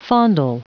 Prononciation du mot fondle en anglais (fichier audio)
Prononciation du mot : fondle